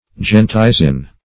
Search Result for " gentisin" : The Collaborative International Dictionary of English v.0.48: Gentisin \Gen"ti*sin\, n. (Chem.)